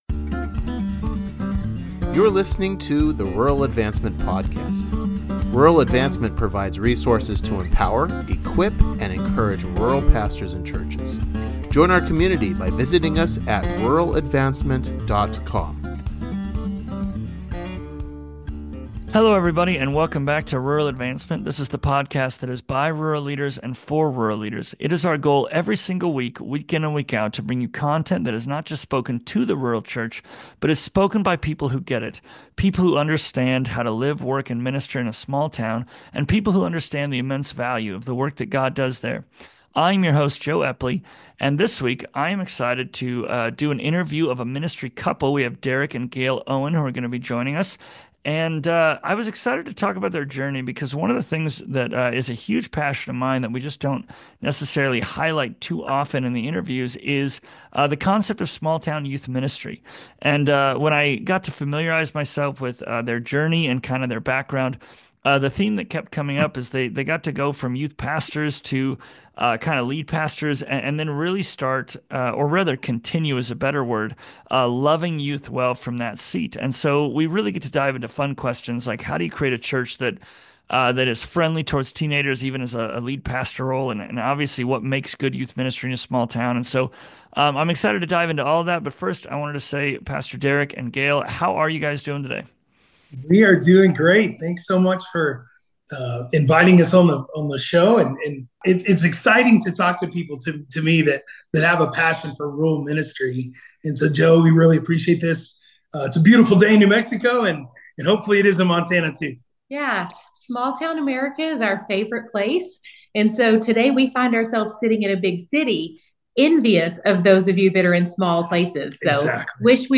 Join us today as they unpack topics such as small town best practices for youth ministry, how to walk through the loss of a student, and how to create a congregation that empowers and embraces young people. This week is Part 1 of 2 for this great conversation!